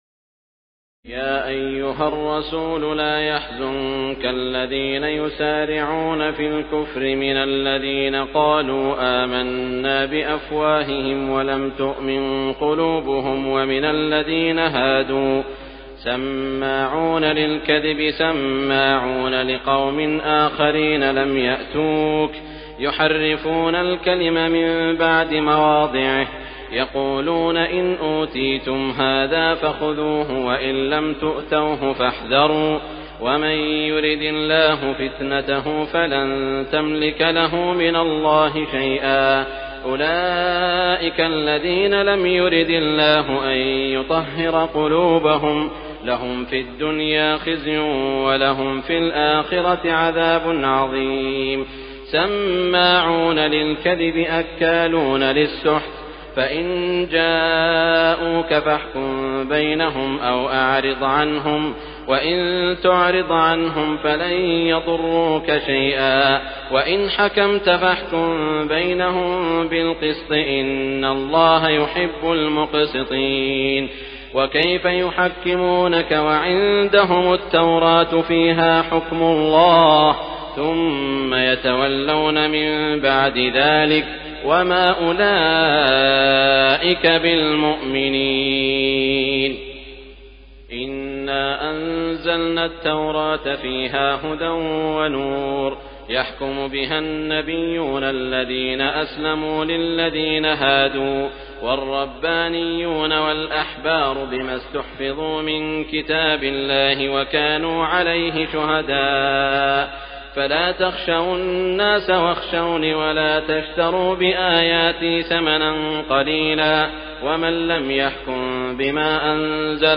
تراويح الليلة السابعة رمضان 1418هـ من سورة المائدة (41-104) Taraweeh 7 st night Ramadan 1418H from Surah AlMa'idah > تراويح الحرم المكي عام 1418 🕋 > التراويح - تلاوات الحرمين